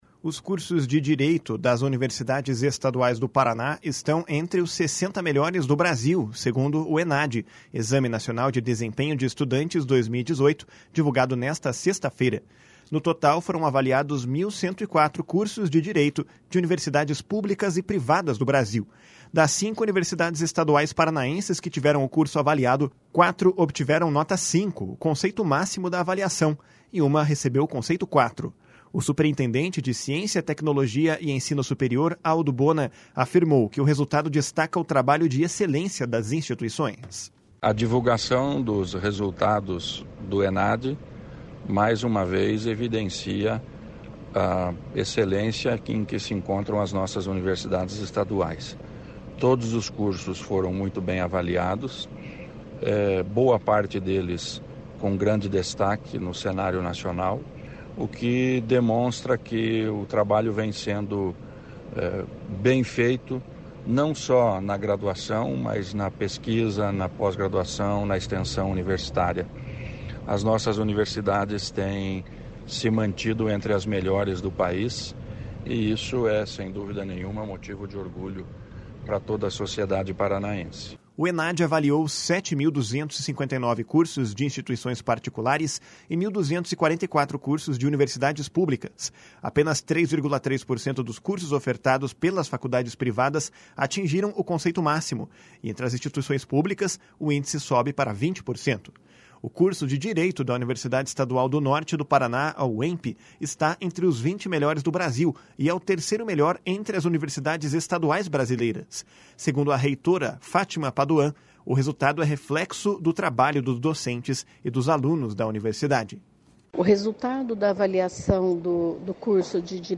O superintendente de Ciência, Tecnologia e Ensino Superior, Aldo Bona, afirmou que o resultado destaca o trabalho de excelência das instituições. // SONORA ALDO BONA //